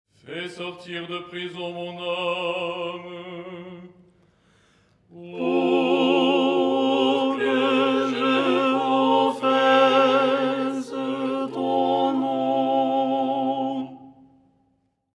Ténor
ton4-03-tenor.mp3